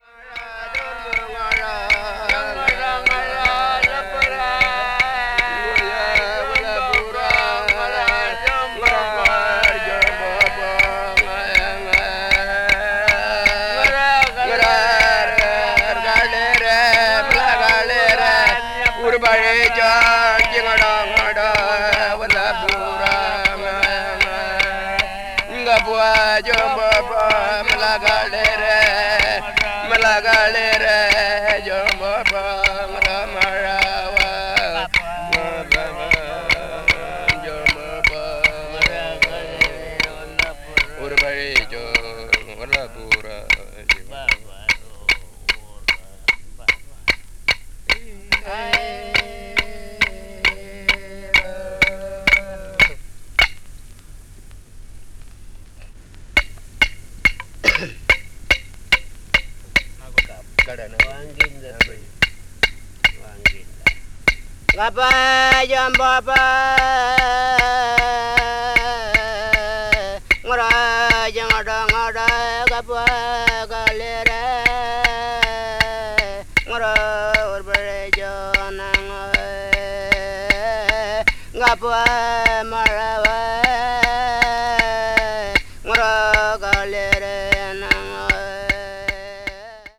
Arnhem Land Popular Classics : Aboriginal Dance Songs with Didjeridu Accompaniment
A Mardag Marajin Chant
aborigini   australia   didjeridu   ethnic music   folk   traditional   world music